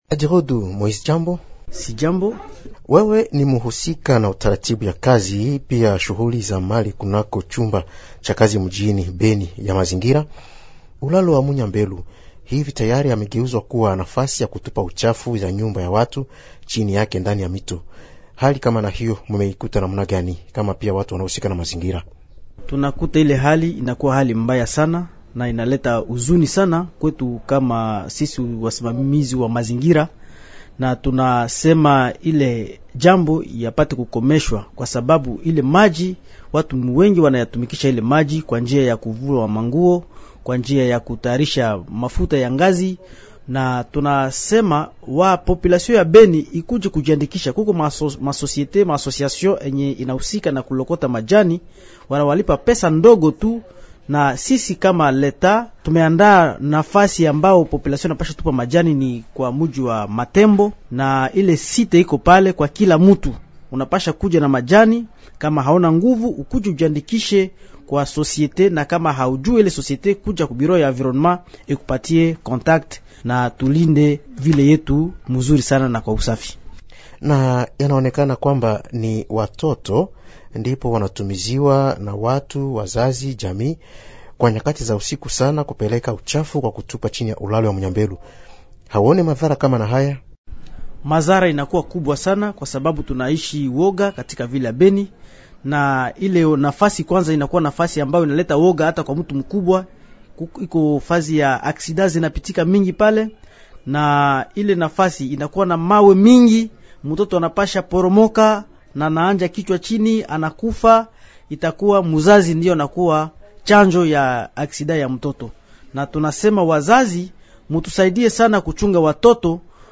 L'invité swahili, Émissions / Institut Supérieur de Management, ISM, étudiants